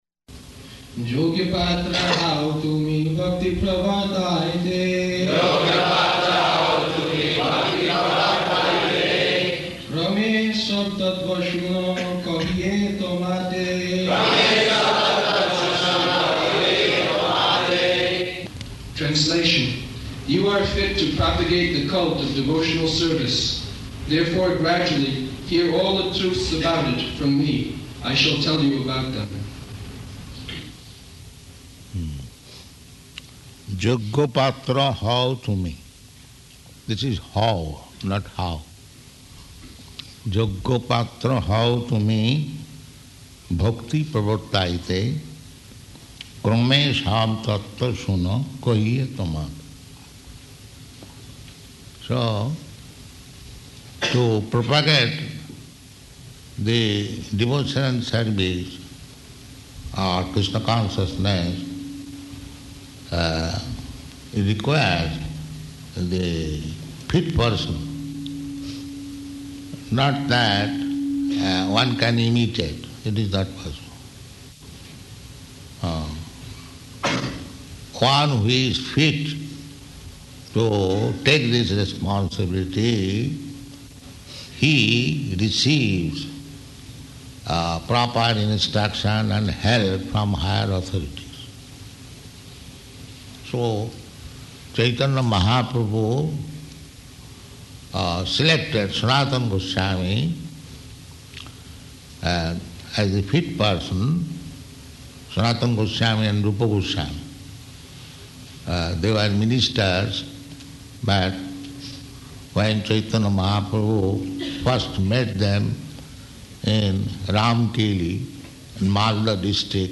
July 13th 1976 Location: New York Audio file
[chants verse, etc.]